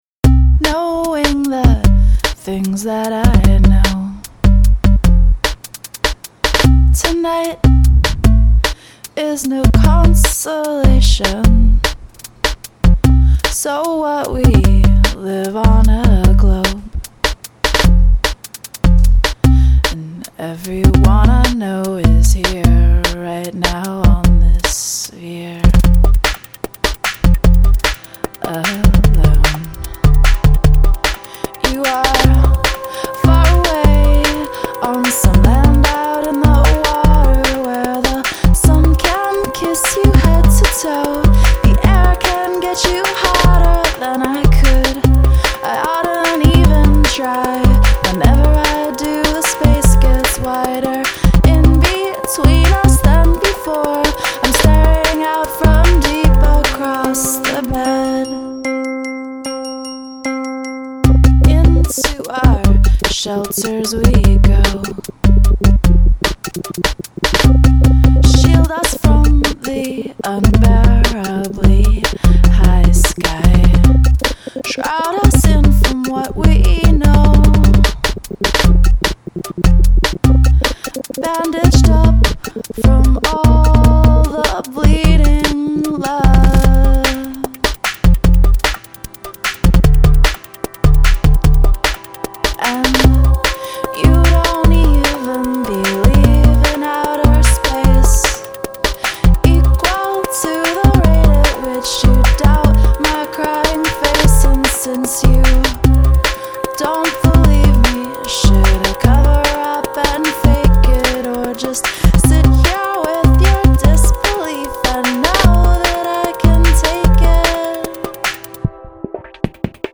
commanding pop statement